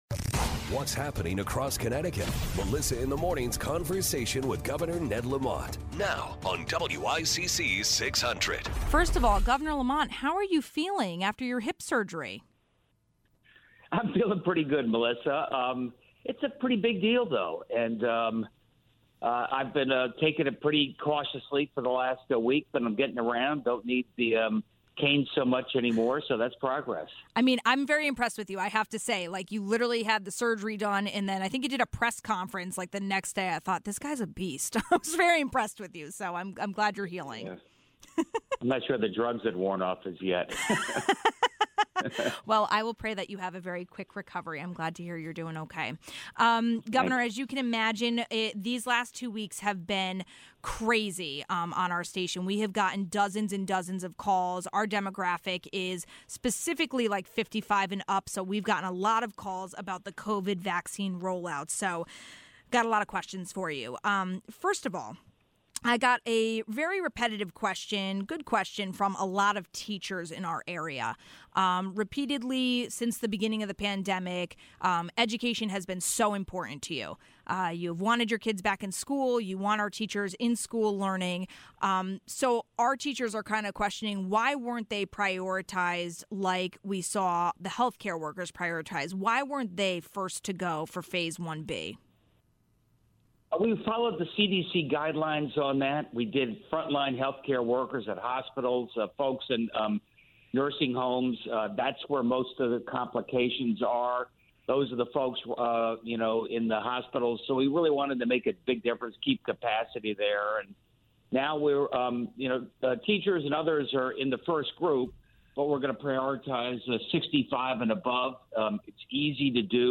1. The governor answered questions about covid vaccine appointments & doses as well as reacts to the Trumbull super spreader party. ((00:00))
State Senator Kevin Kelly explains the challenge of tax increases for the middle classes.